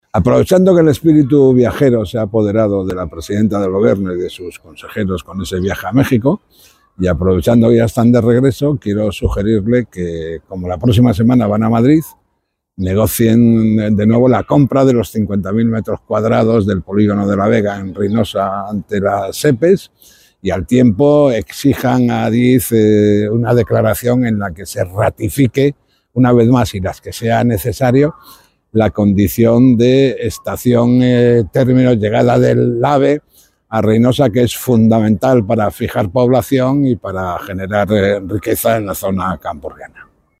Ver declaraciones de Francisco Javier López Marcano, diputado del Partido Regionalista de Cantabria y portavoz del PRC en materia de Industria,